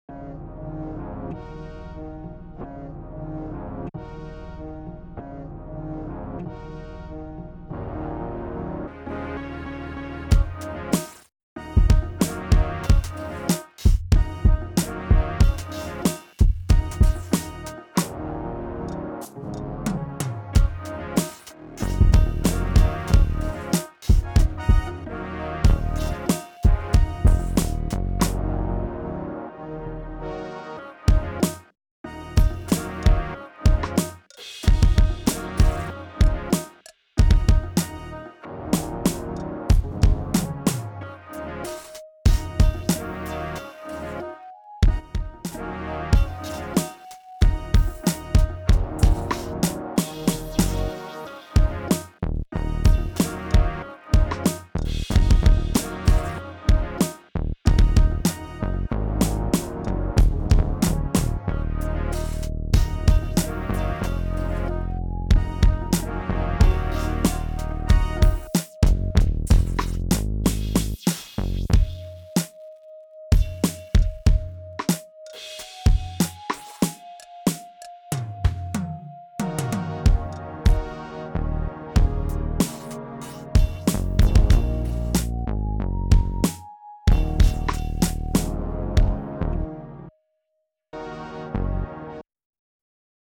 instrumentale musik sample
ich habe midi cc1 und midi cc11 (dynamics + expression) verwendet.